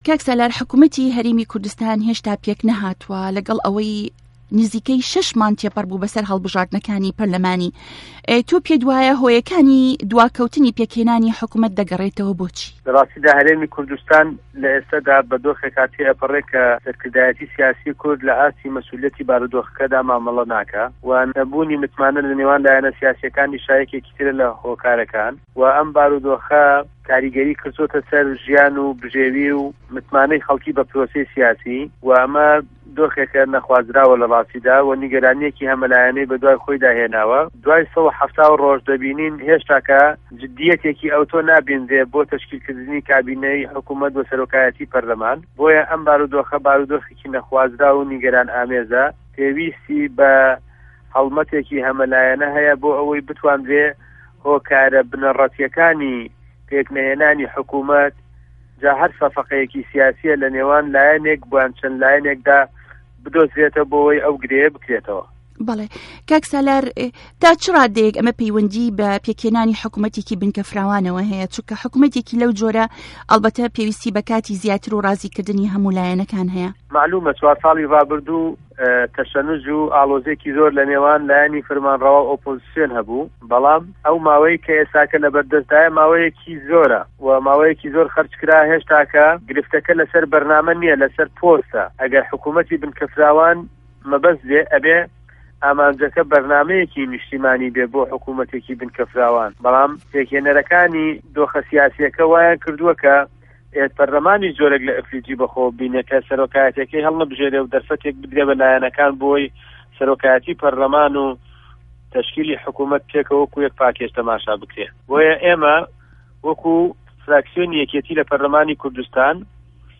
بۆ وه‌ڵامدانه‌وه‌ و تاووتوێکردنی ئه‌م پرسیارانه‌، به‌شی کوردی ده‌نگی ئه‌مه‌ریکا گفتووگۆیه‌کی له‌گه‌ڵ سالار مه‌حمود ئه‌ندامی په‌رله‌مانی هه‌رێمی کوردستان سازکرد.
گفتوگۆ ڵه‌گه‌ڵ سالار مه‌حمود 10ی 3ی ساڵی 2014